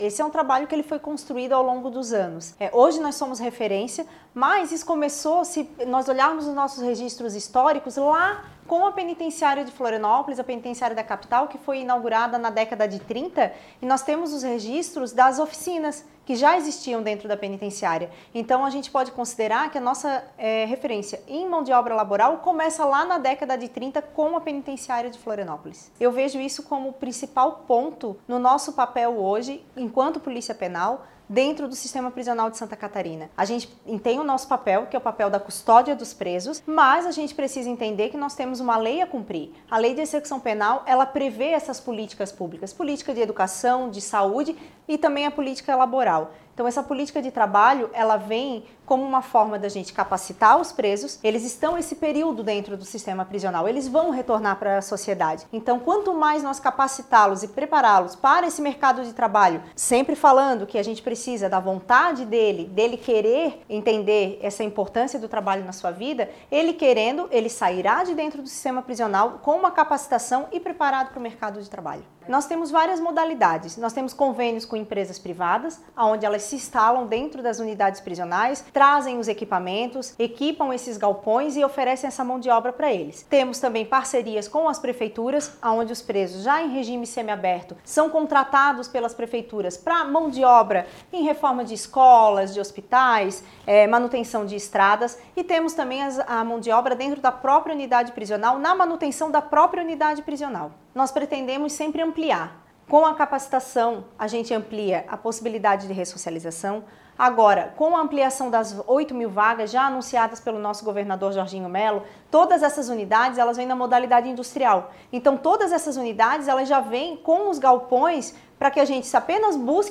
A secretária de Estado da Justiça e Reintegração Social, Danielle Amorim Silva, ressaltou a importância da preparação do apenado para o retorno à vida e o acesso ao mercado de trabalho: